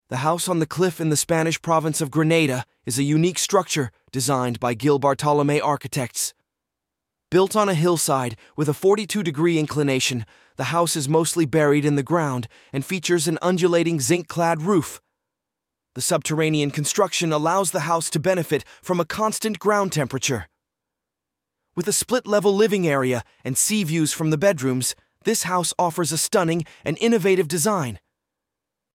Quick Summary Audio